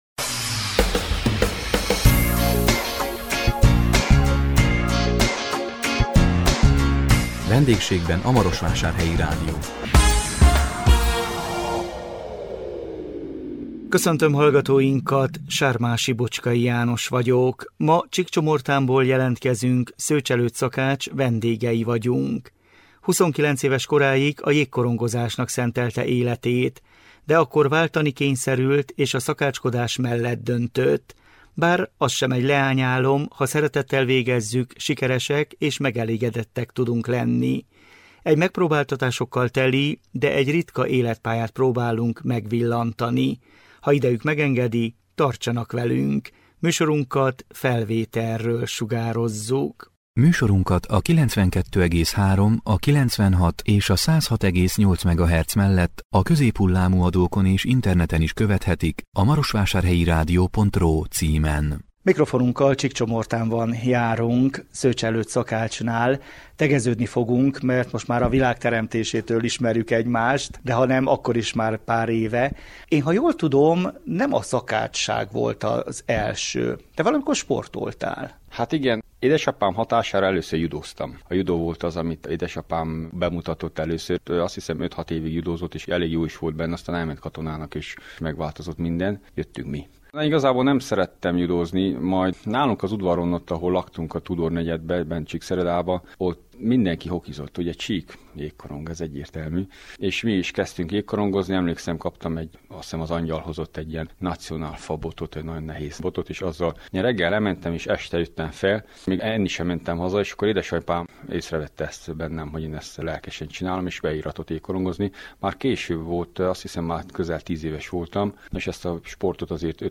A 2026 március 5-én közvetített VENDÉGSÉGBEN A MAROSVÁSÁRHELYI RÁDIÓ című műsorunkkal Csíkcsomortánból jelentkeztünk